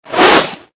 General_throw_miss.ogg